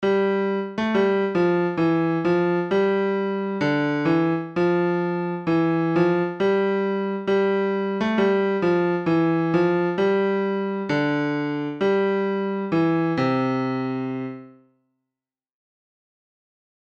CAN YOU PLAY THESE SONGS ON YOUR PIANO?